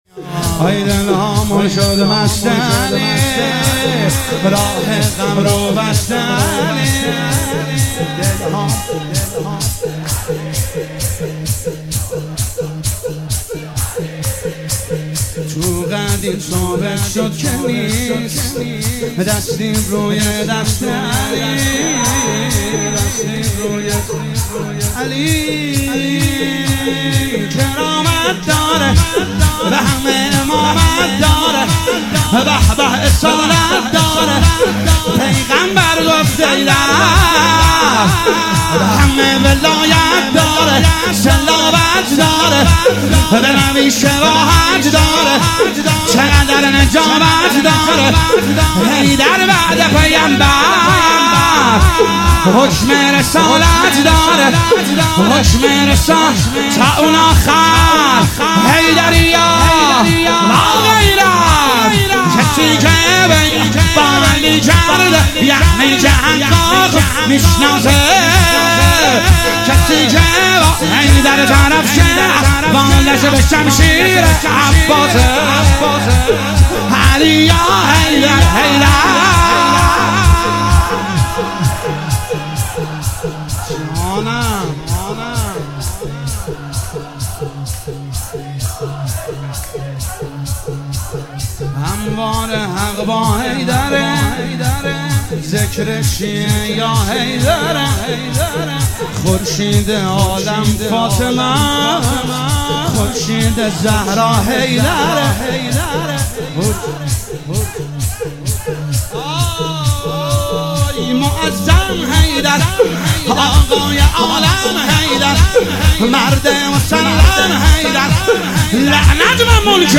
هیئت بهشت العباس قم